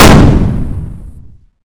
Heavy Weapons And Explosions Sound Effects – Heavy-weapon-002-single-shot – Free Music Download For Creators
Heavy_Weapons_And_Explosions_Sound_Effects_-_heavy-weapon-002-single-shot.mp3